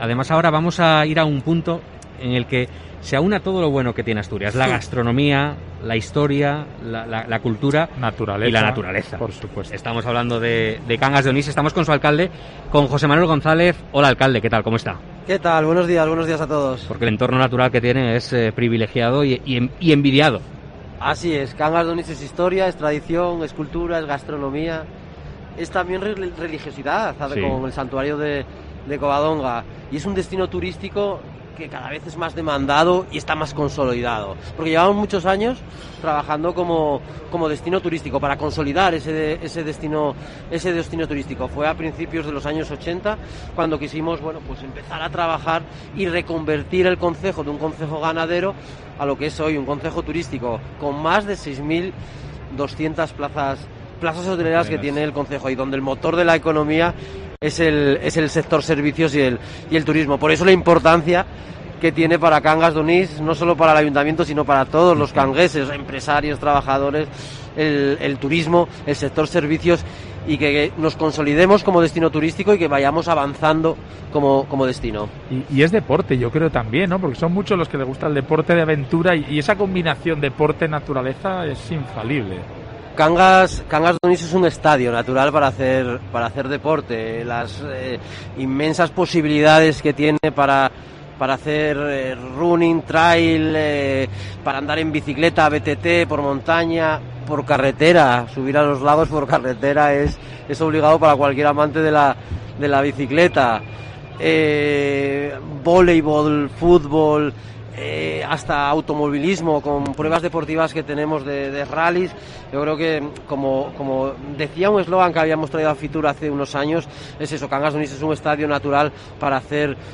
El alcalde, José Manuel González, ha estado en el especial de COPE Asturias desde la Feria Internacional de Turismo para hablar del afianzamiento del sector en el concejo
Fitur 2022: Entrevista a José Manuel González, alcalde de Cangas de Onís